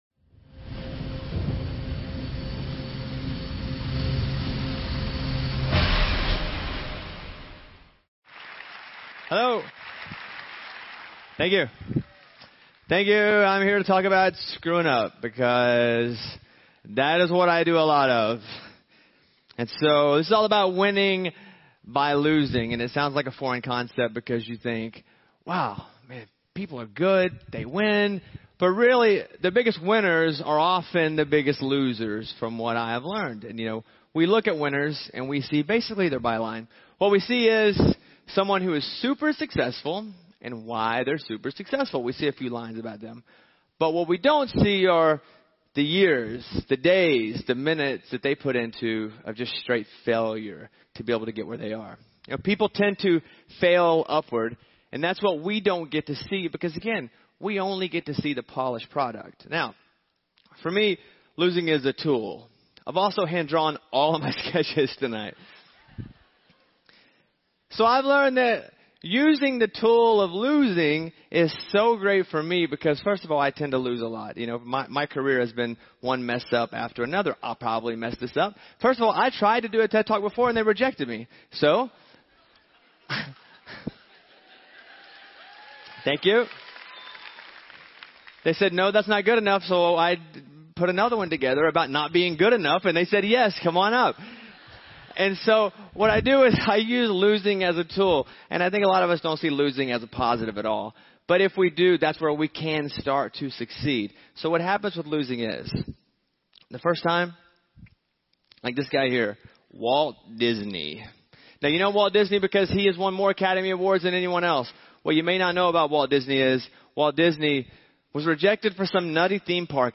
Here is the full transcript of radio personality Bobby Bones’ TEDx Talk presentation on My Story: Winning By Losing at TEDxNashville conference. This event occurred on March 17, 2017 at Nashville, Tennessee.